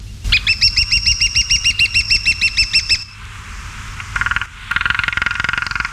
Pic épeichette
Dendrocopos minor
epeichette.mp3